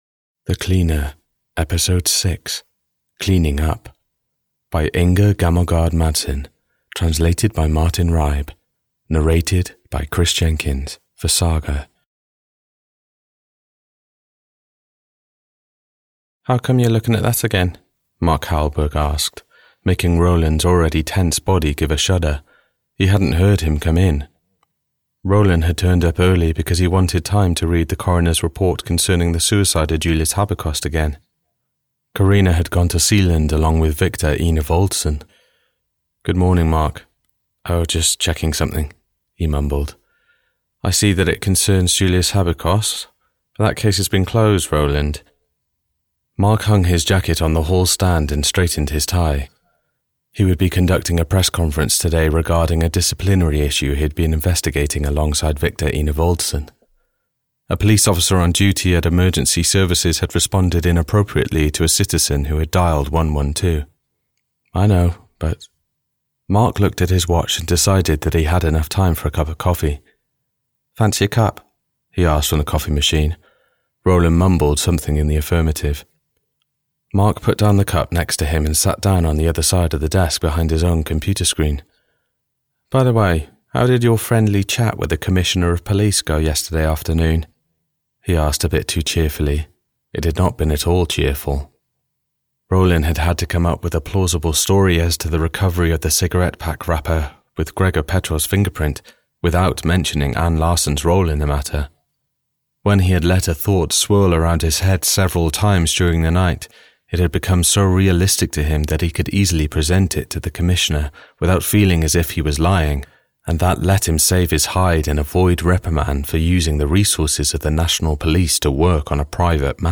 The Cleaner 6: Cleaning Up (EN) audiokniha
Ukázka z knihy